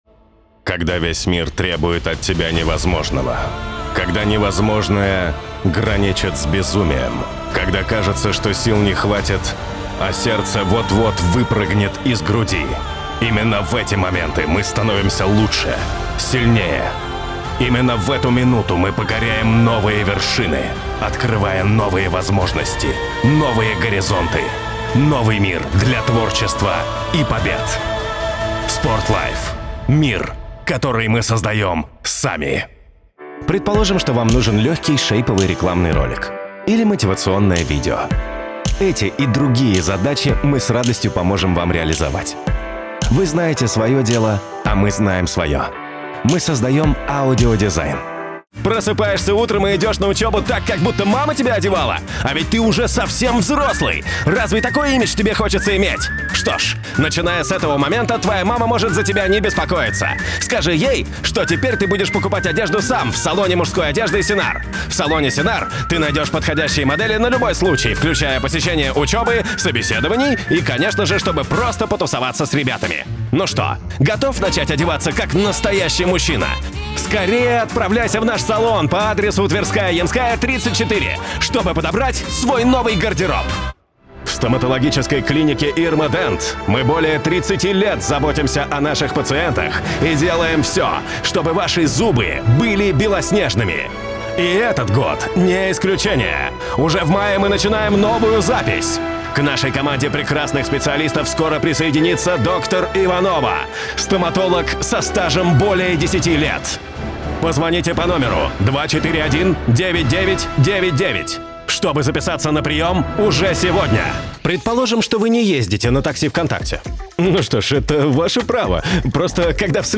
Муж, Рекламный ролик/Средний